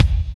25.05 KICK.wav